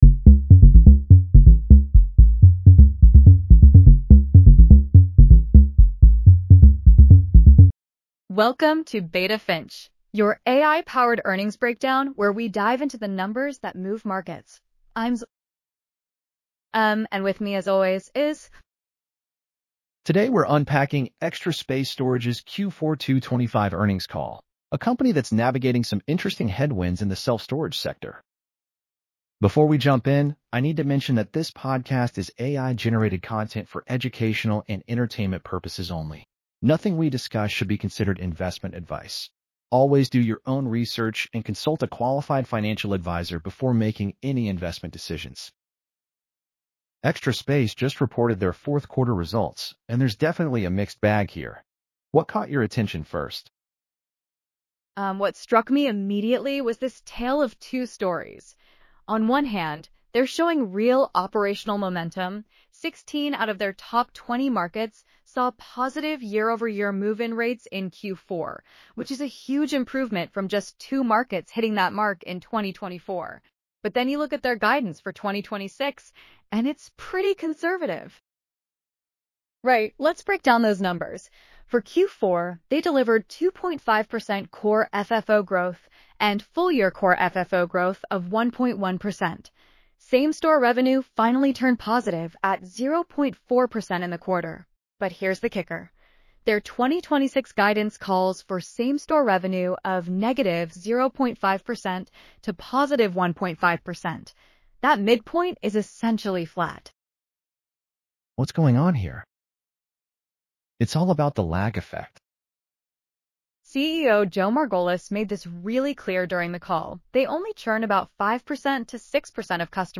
• AI-generated insights and analysis